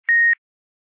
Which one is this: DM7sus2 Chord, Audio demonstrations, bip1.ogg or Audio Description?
bip1.ogg